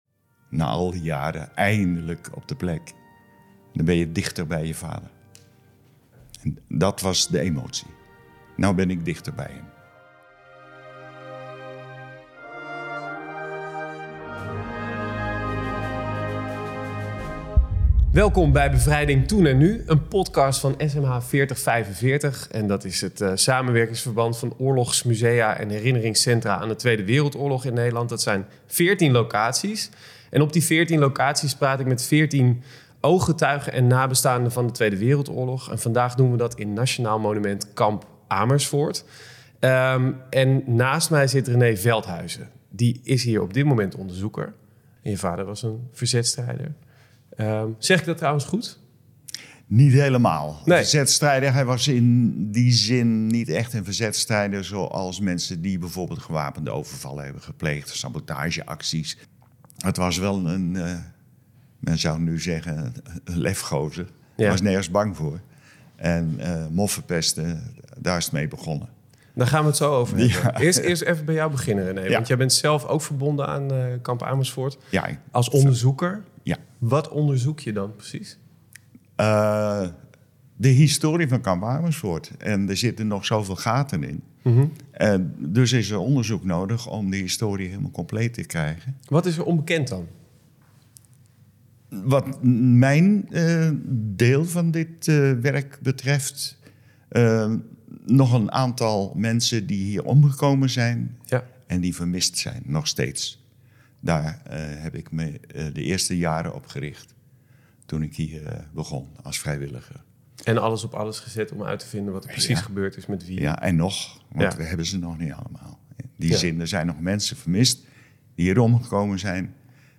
In deze aflevering vanuit Nationaal Monument Kamp Amersfoort